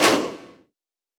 Perc [Stomp](1).wav